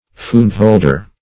Search Result for " fundholder" : The Collaborative International Dictionary of English v.0.48: Fundholder \Fund"hold"er\, a. One who has money invested in the public funds.